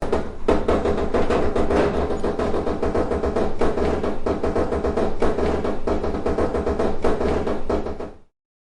Watermill Wooden Wheel 2
Watermill Wooden Wheel 2 is a free nature sound effect available for download in MP3 format.
Watermill Wooden Wheel 2.mp3